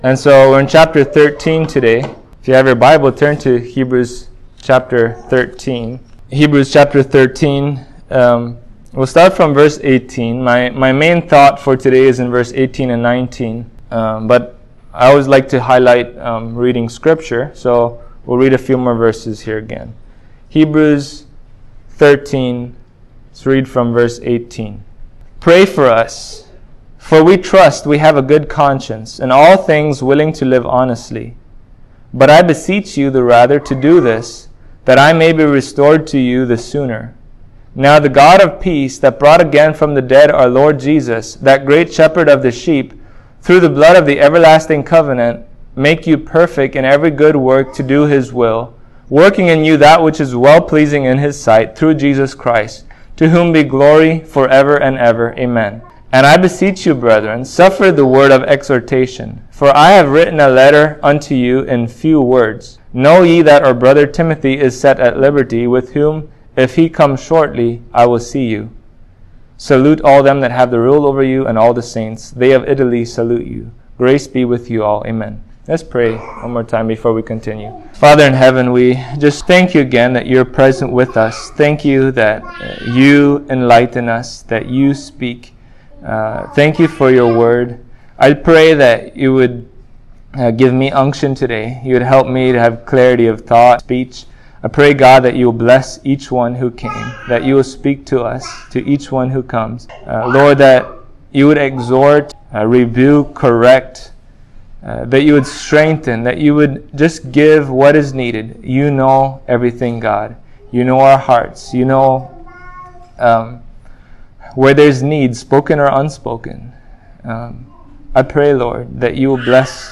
Heb 13:18-19 Service Type: Sunday Morning Should we ask others to pray for us?